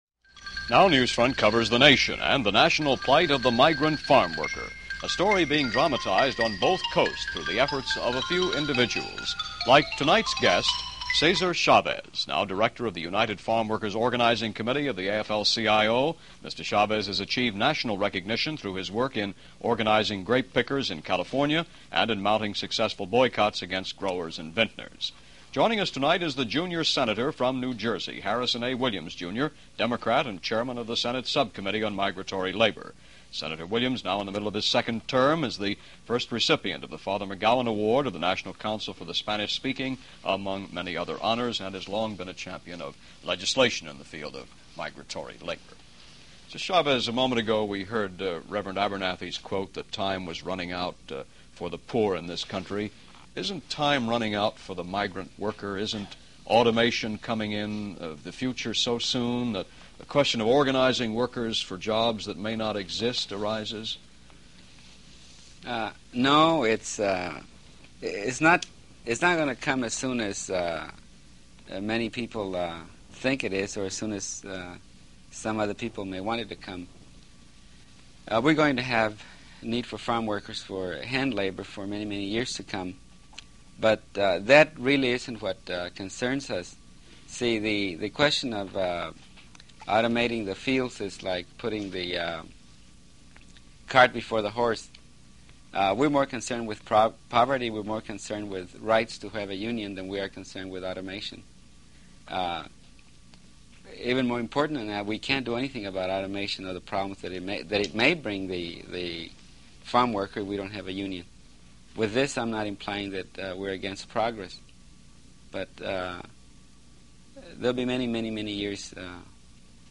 A Word From Cesar Chavez - May 17, 1968 - An interview with the founder of The United Farm Workers - Past Daily Reference Room.